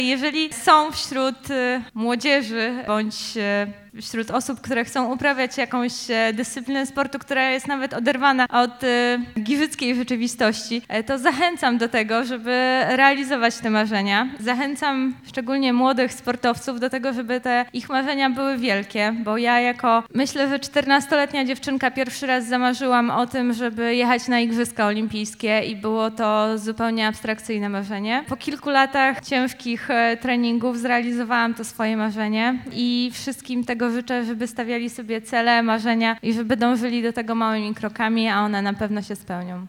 Utytułowana panczenistka była gościem specjalnym uroczystości wręczenia Nagród Burmistrza Giżycka w dziedzinie sportu i kultury za rok 2016.
– Nie bójcie się marzyć – zachęcała Złotkowska giżycką młodzież.